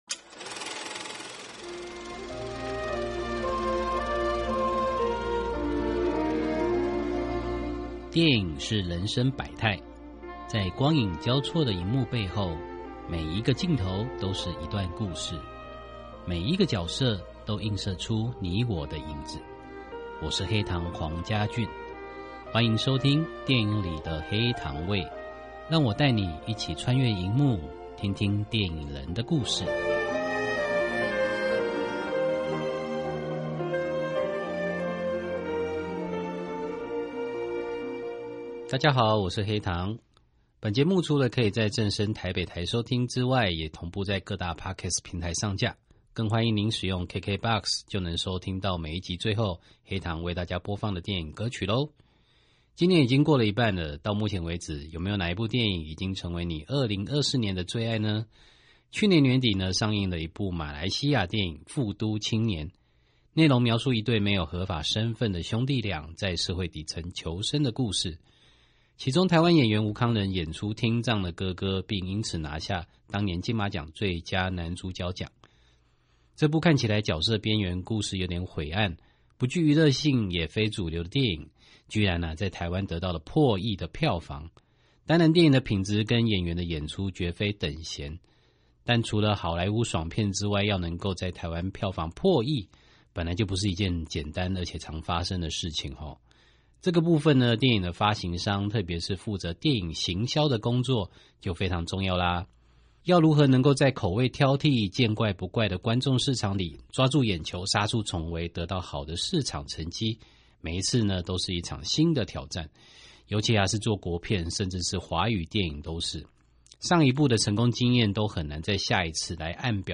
訪談內容：